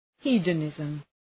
Προφορά
{‘hi:də,nızm}
hedonism.mp3